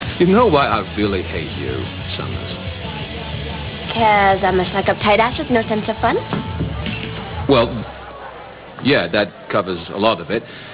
Here you will find various sounds taken from Buffy, the Vampire Slayer.